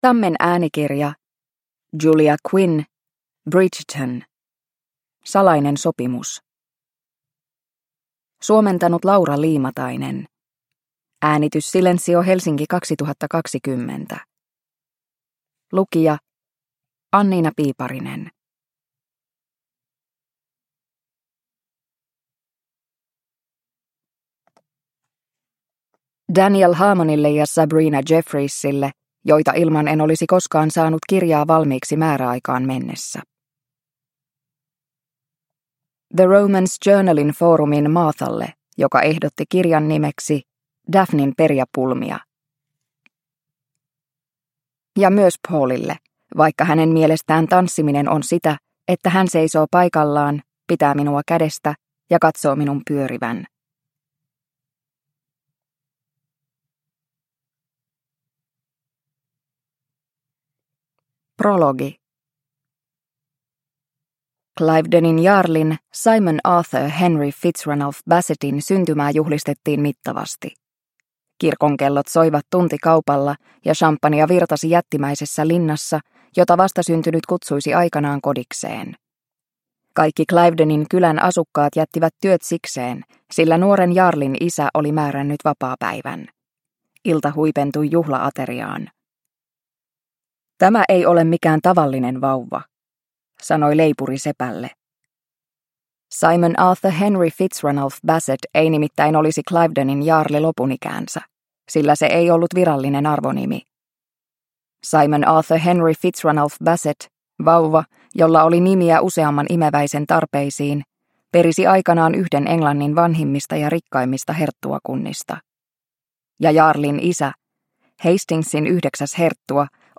Bridgerton: Salainen sopimus – Ljudbok – Laddas ner